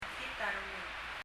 mekngit a rengul [mə(k)ŋit ə rəŋəl] ちょっと聴き取りにくいですが
傍らで聴いていても、 mekngit の [mə(k)] はほとんど聴こえず、[ŋitərəŋəl] と しか聴こえませんでした。